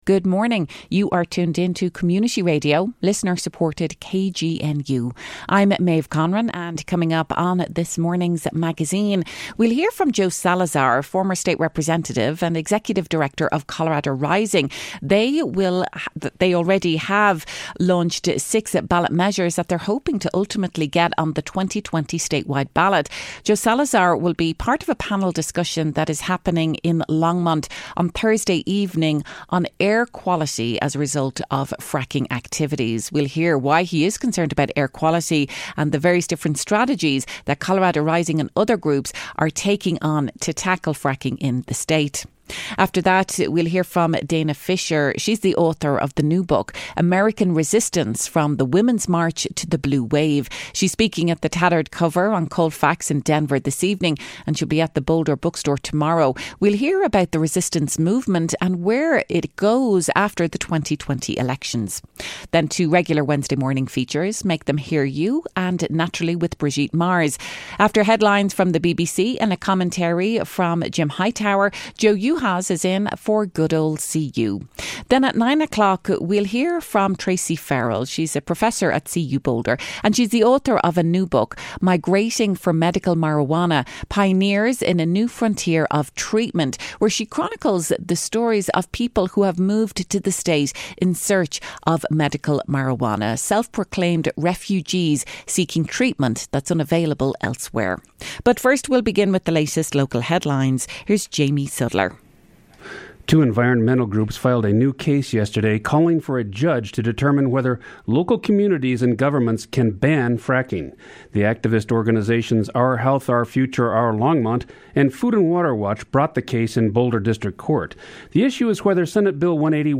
Then, an interview